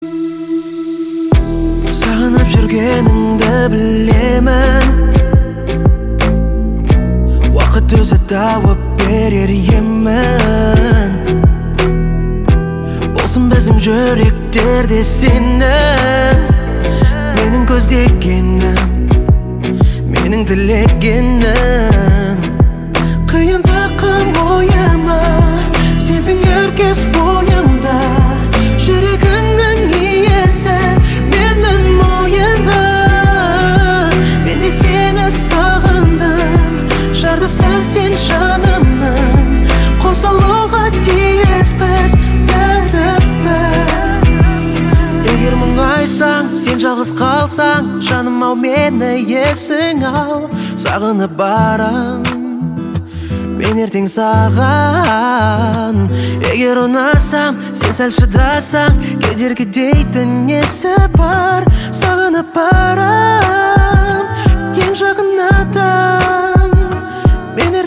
• Качество: 175, Stereo
поп
гитара
красивый мужской голос